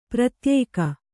♪ pratyēka